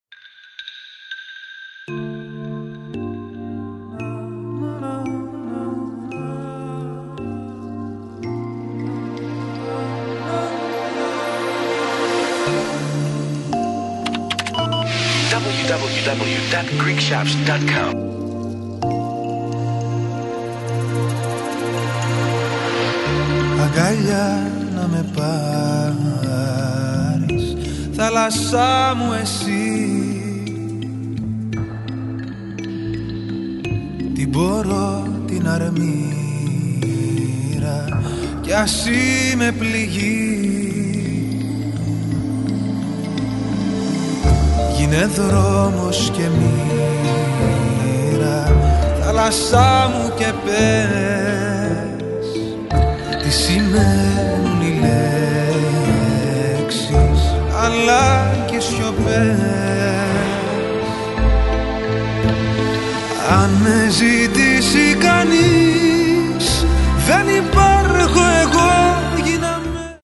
The limited edition album features 11 new modern pop tracks.